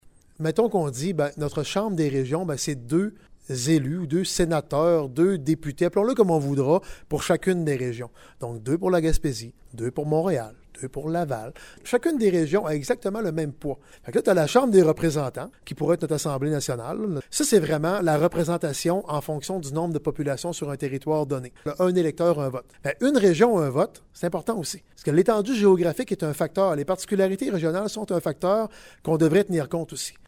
Daniel Côté explique que la Ville propose plutôt d’instaurer un système semblable aux États-Unis, avec une chambre des représentants élus par la population et une autre composée de sénateurs qui représentent chacun des états, peu importe le nombre de personnes: